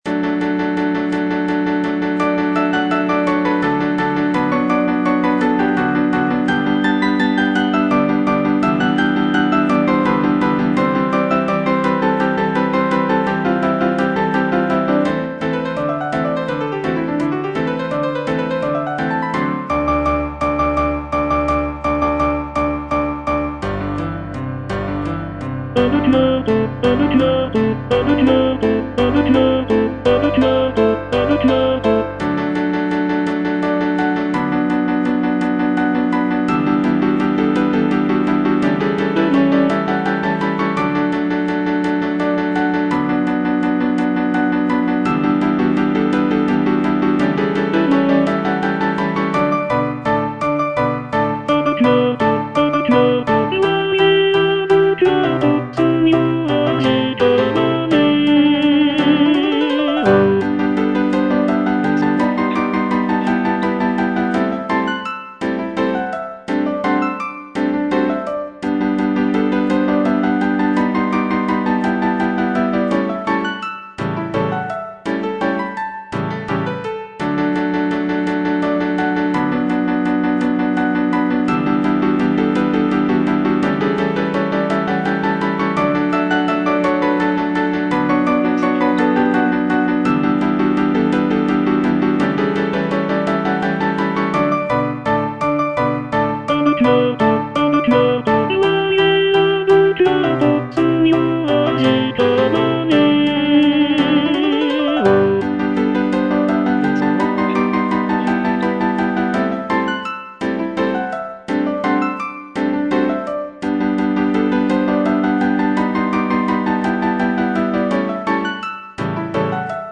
G. BIZET - CHOIRS FROM "CARMEN" A deux cuartos (tenor II) (Voice with metronome) Ads stop: auto-stop